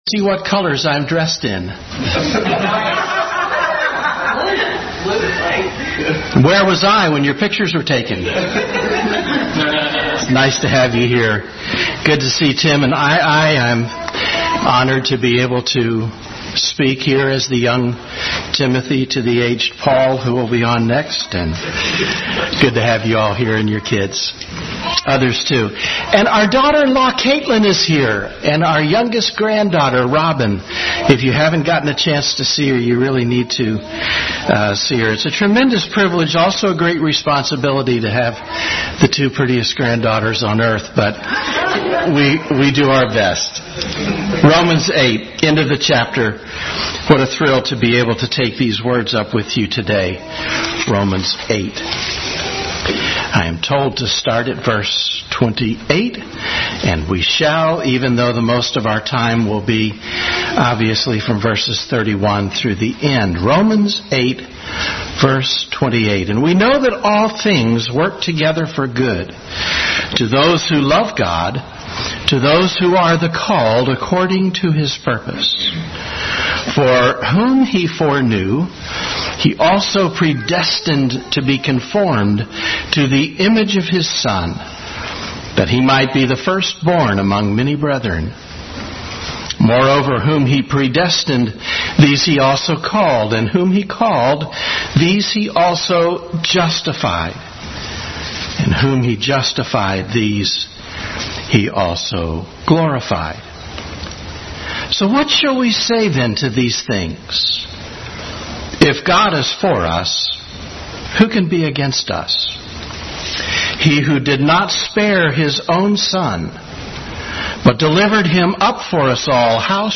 Bible Text: Romans 8:28-39, Genesis 22:1-19, Psalm 44:22 | Adult Sunday School. Continued study in the book of Romans.